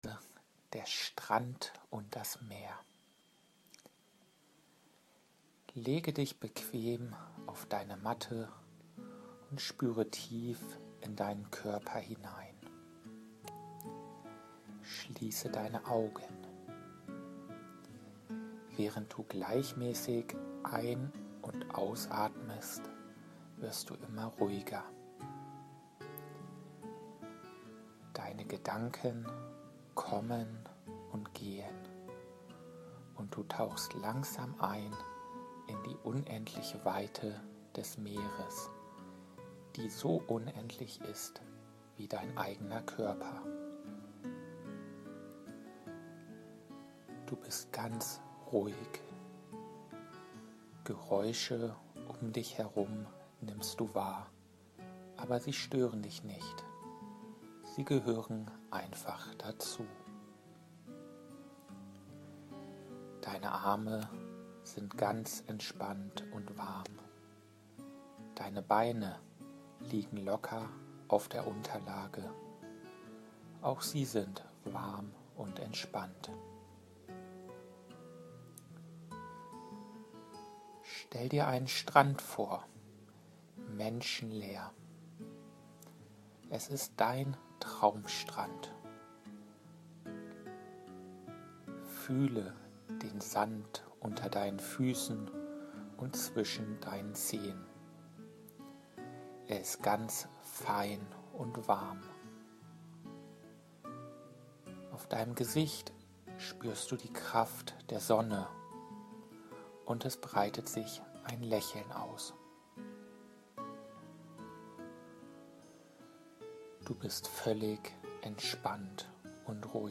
fantasiereise-der-strand-und-das-meer-mit-musik.mp3